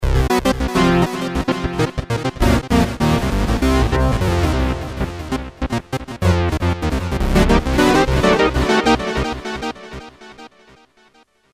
Programmable Polyphonic Synthesizer
demo sound with delay